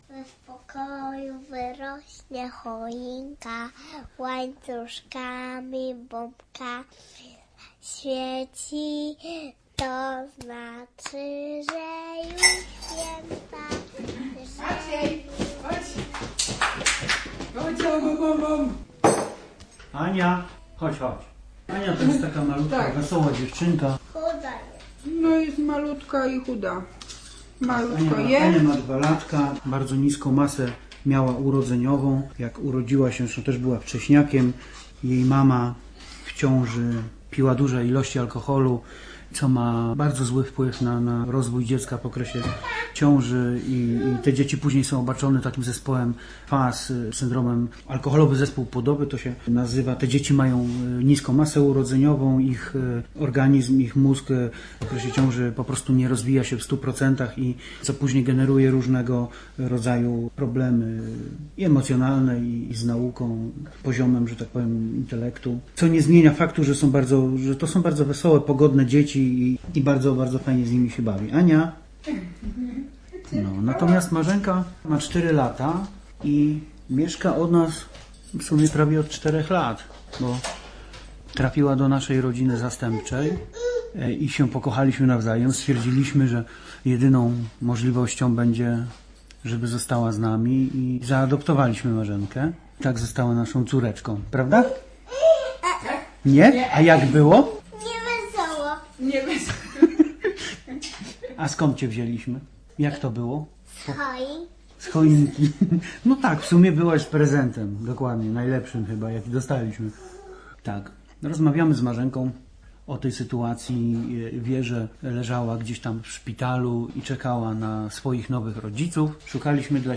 Hej, Kolęda! - reportaż